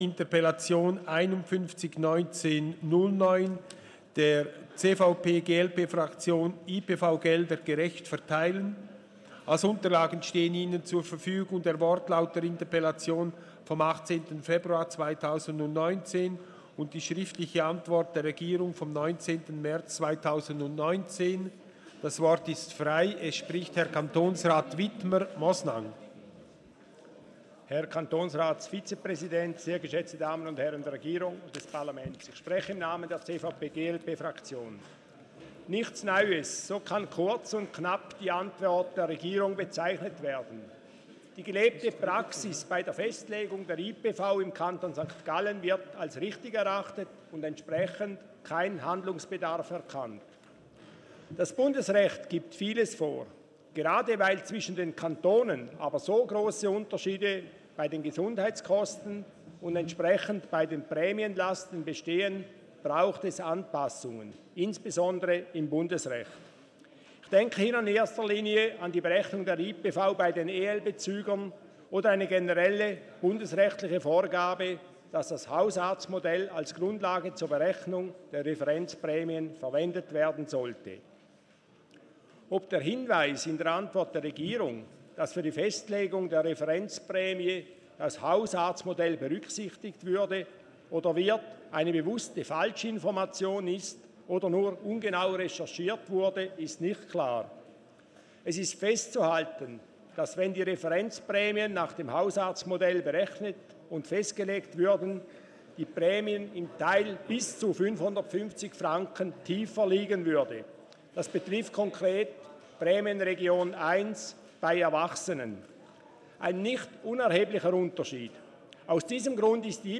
24.4.2019Wortmeldung
Session des Kantonsrates vom 23. und 24. April 2019